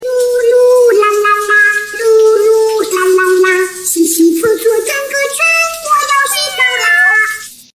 Munchkin cat singing oh oh La la
u3-Munchkin-cat-singing-oh-oh-La-la.mp3